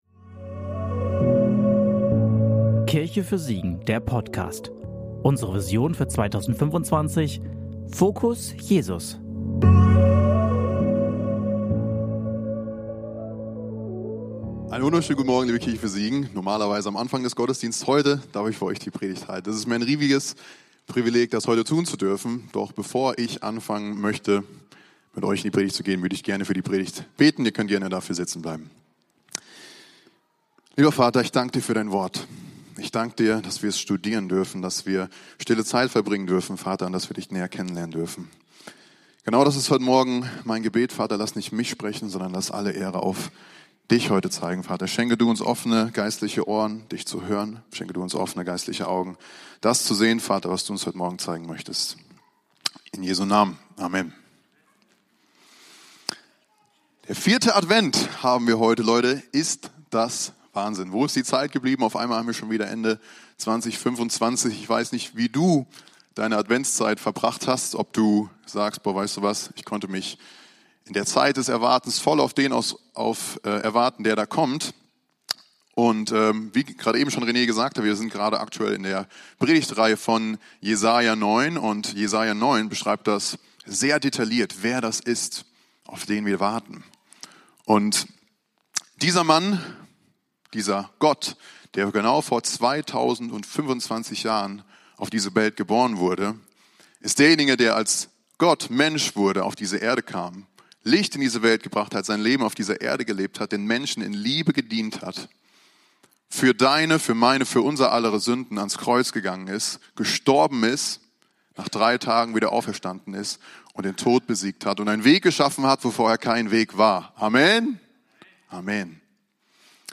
Der dritte Teil der Predigtserie "Frieden auf Erden".
Predigt vom 21.12.2025 in der Kirche für Siegen